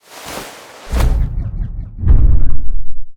Sfx_tool_hoverpad_unpark_left_01.ogg